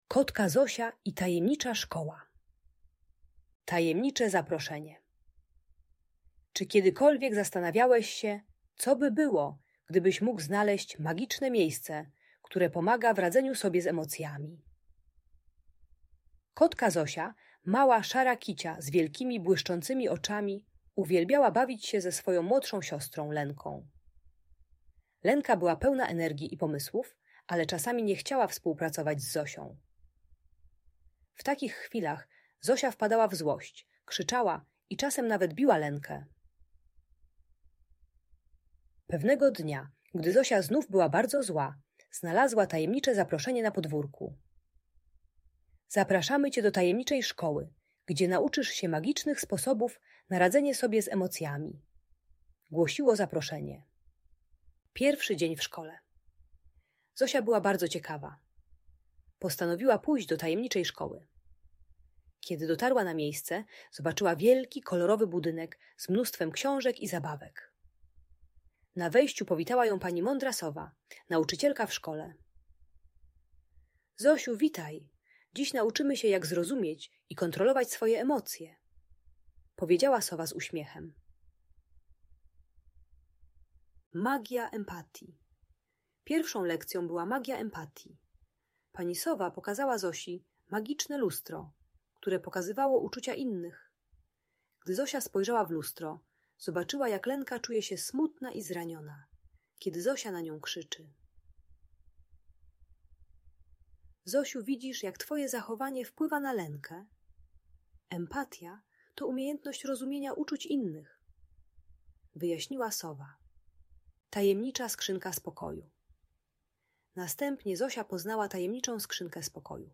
Kotka Zosia i Tajemnicza Szkoła - Audiobajka